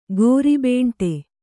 ♪ gōri bēṇṭe